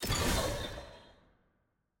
sfx-jfe-ui-generic-rewards-click.ogg